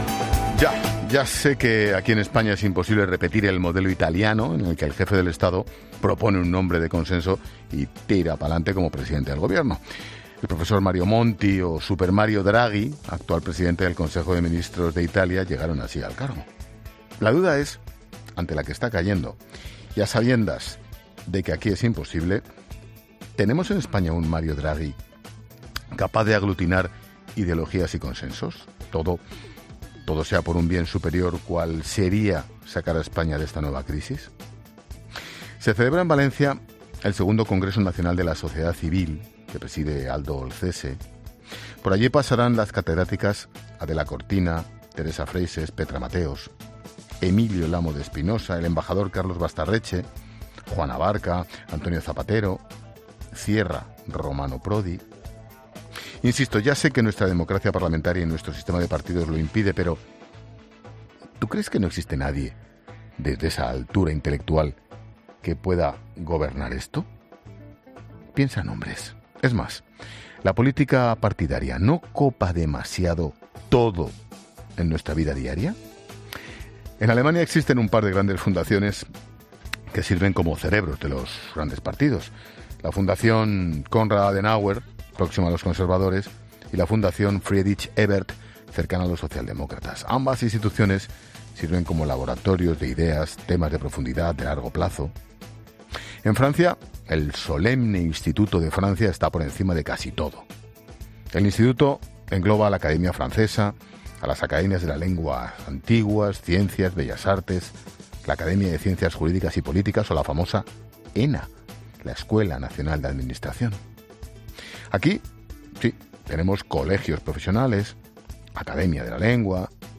AUDIO: El director de 'La Linterna' habla de la actualidad en su monólogo de este martes